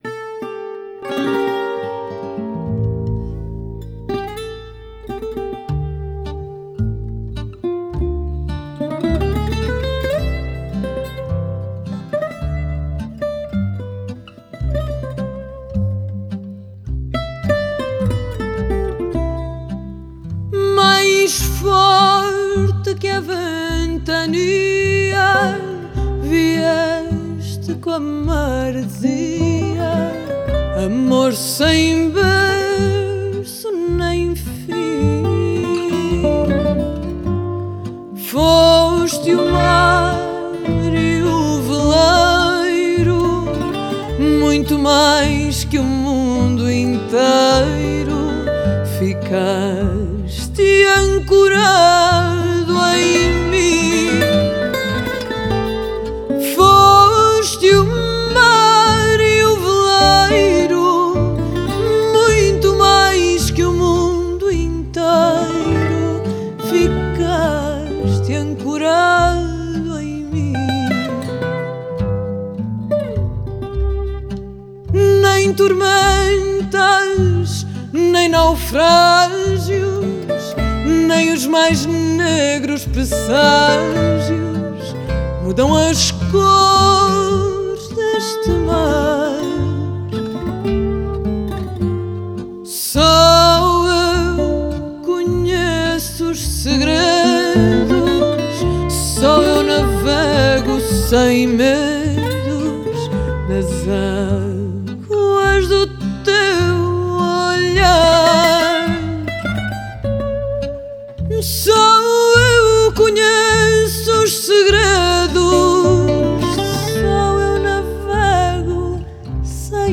Genre: Folk, World, & Country
Style: Fado